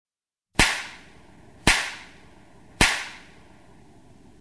slapstick.mp3